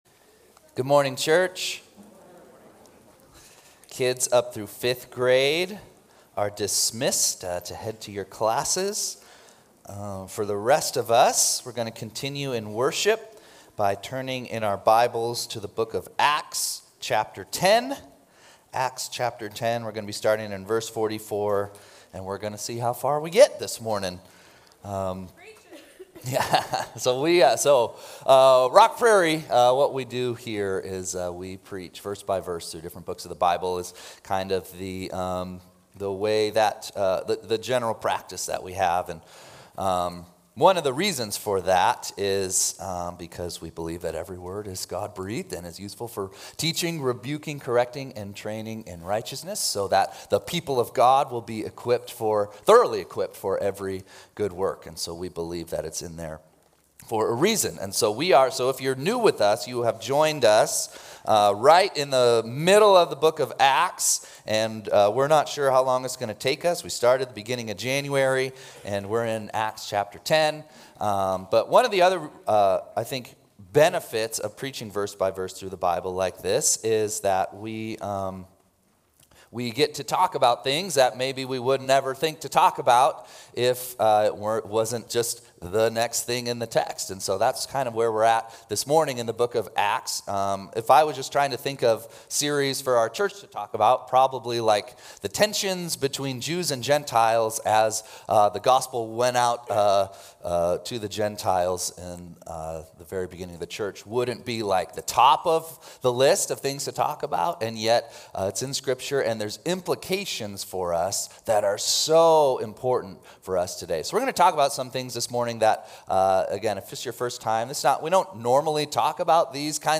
7-6-25-Sunday-Service.mp3